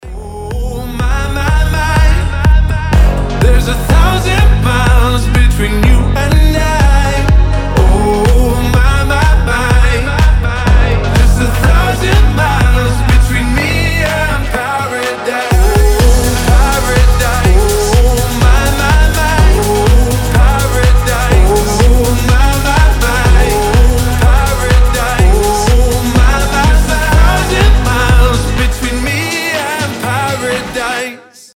• Качество: 320, Stereo
красивый мужской голос
house